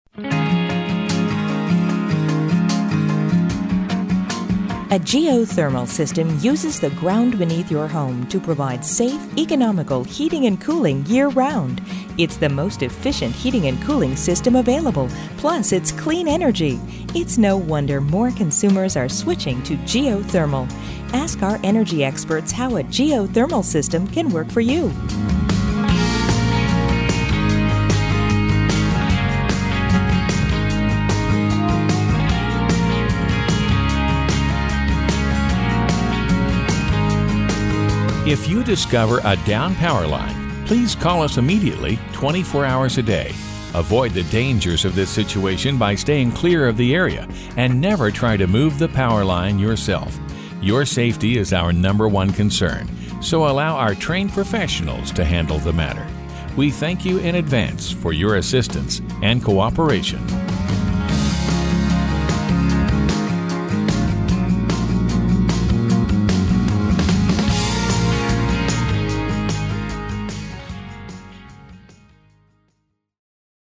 Messages on hold
Music on hold